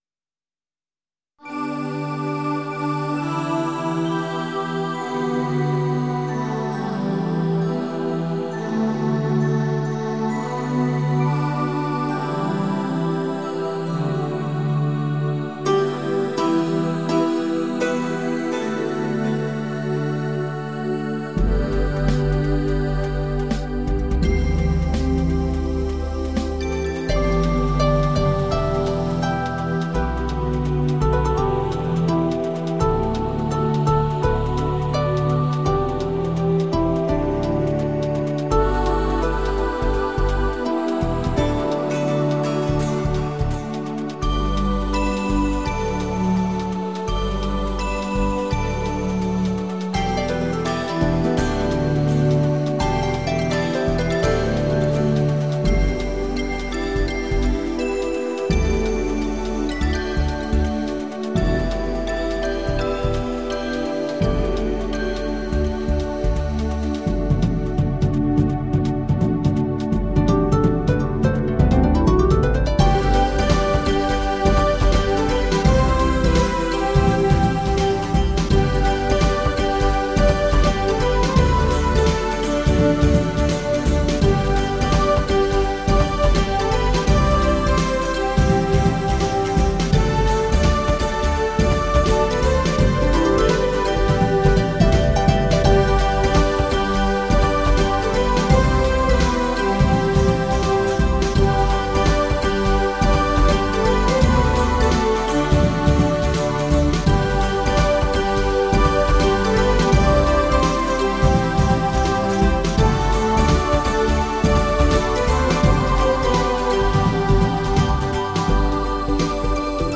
据说此系列采用了不用话筒而直接录音的手段，音色、动态非一般天碟可比。